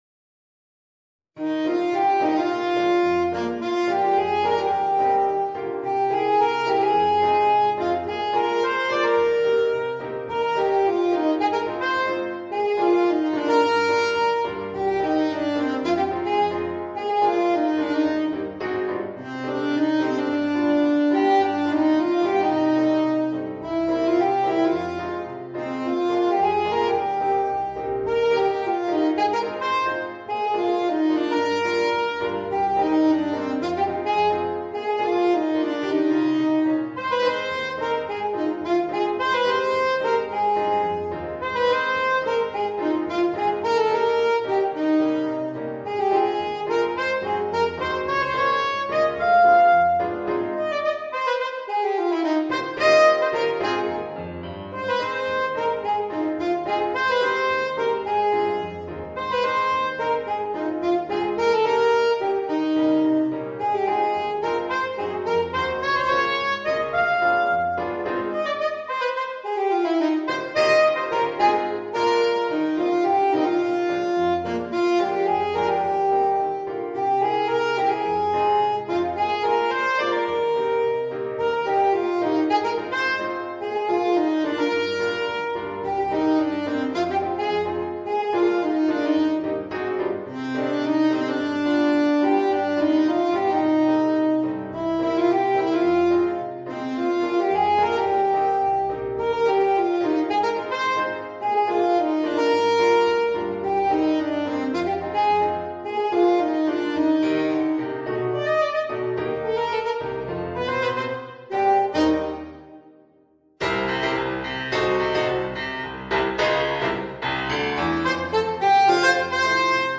Voicing: Alto Saxophone w/ Audio